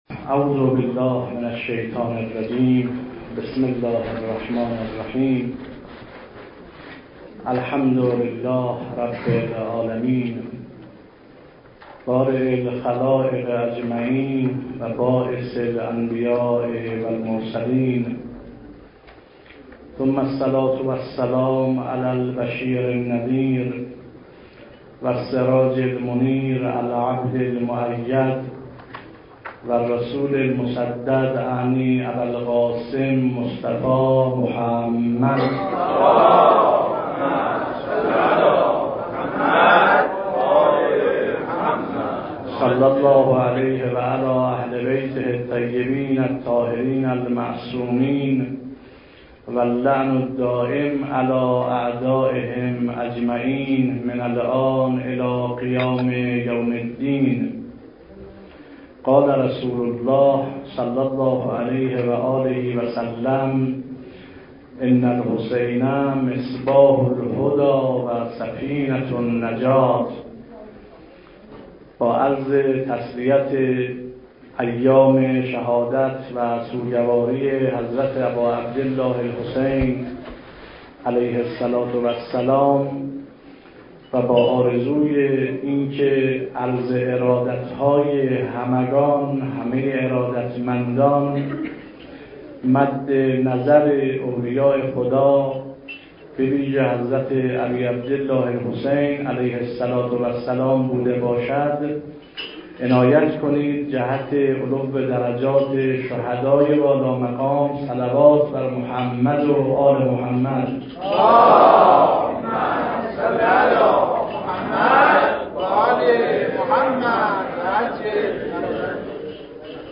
سخنرانی
تخصص وعظ و خطابه آثار صوتی ارسال پیام نام شما دیدگاه درباره فرمتهای متنی ذخیره